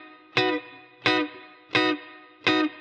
DD_TeleChop_85-Bmaj.wav